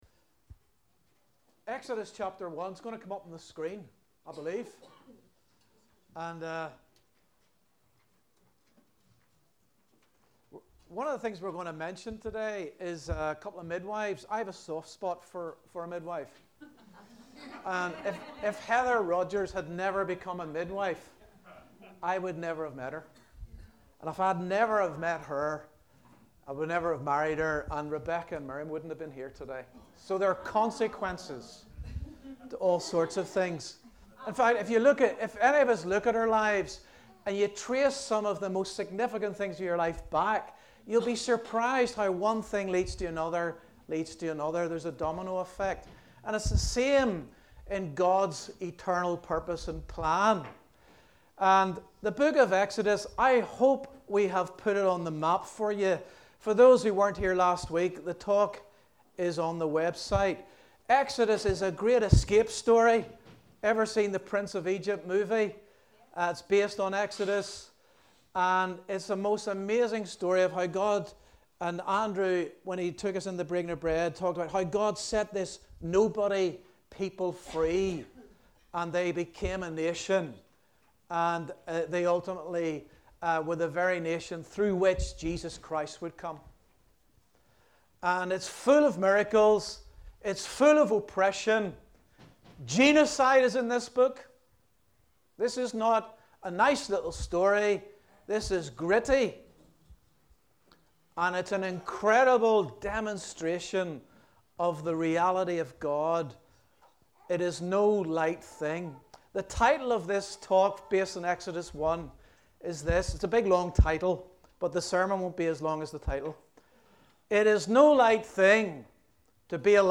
A message from the series "All Messages."